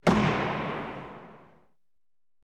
Звуки лука, стрел
Точное попадание в цель с близкого расстояния